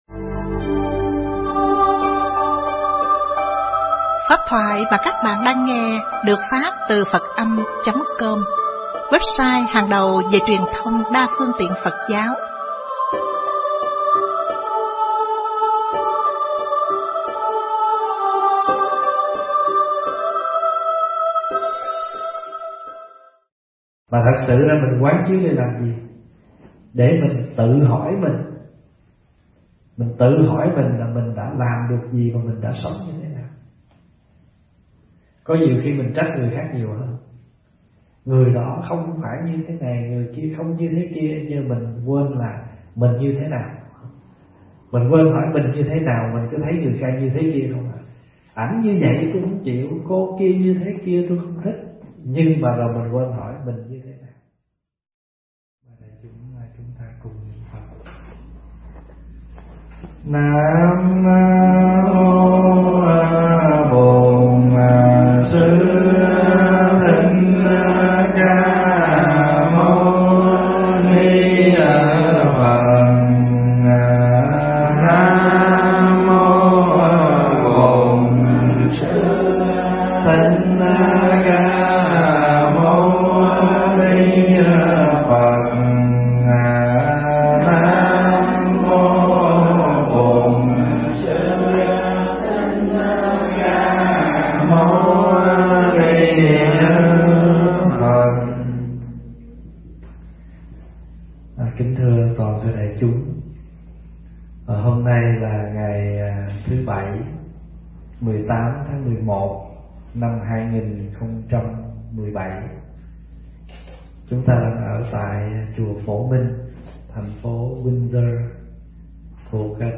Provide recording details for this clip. thuyết giảng tại Chùa Phổ Minh, Windsor ON